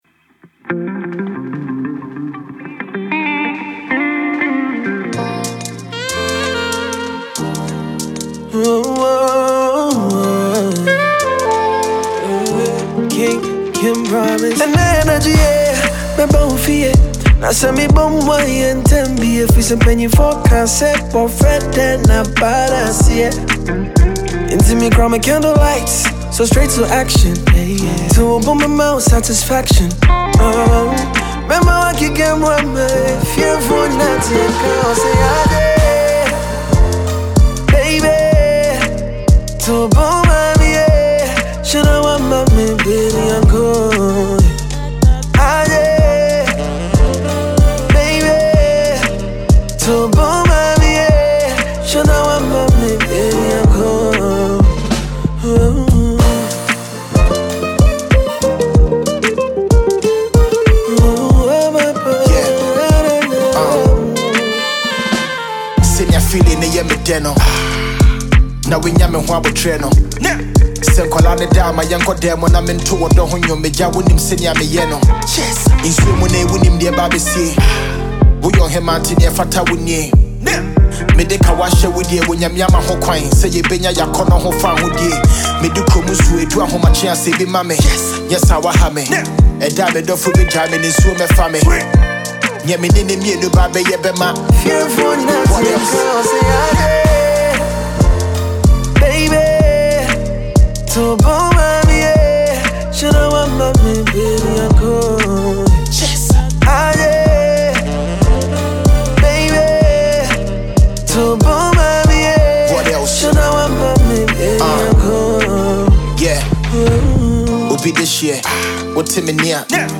Ghana Music
Ghanaian rapper